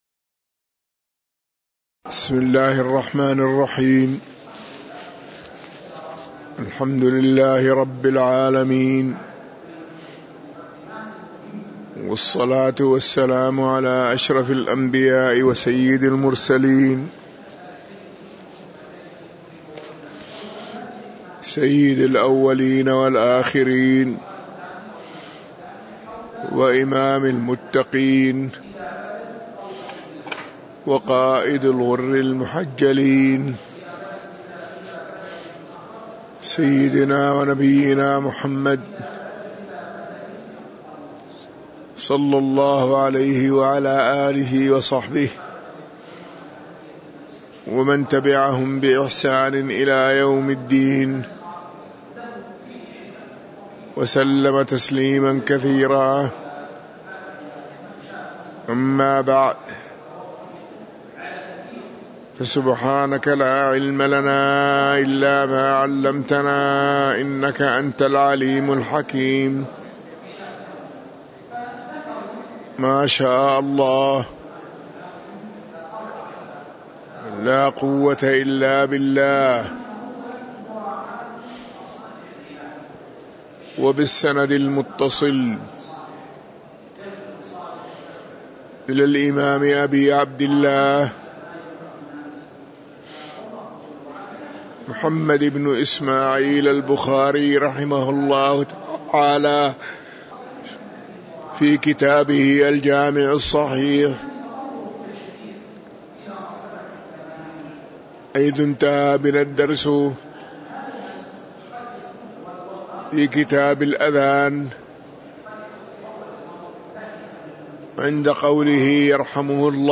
تاريخ النشر ١٠ ربيع الثاني ١٤٤٣ هـ المكان: المسجد النبوي الشيخ